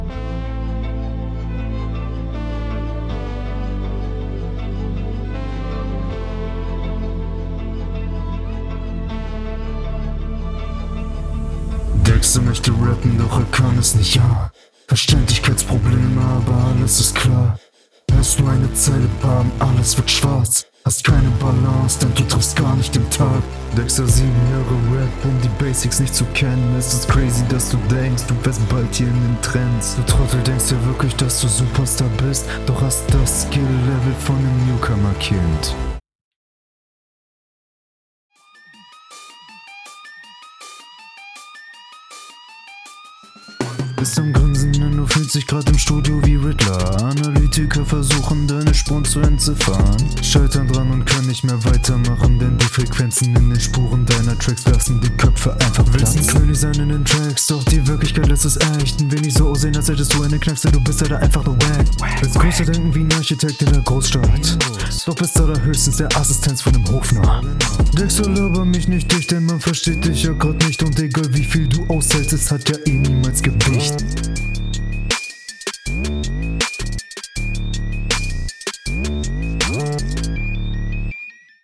Zur Audioqualität kann ich eigentlich das gleiche sagen wie zuvor.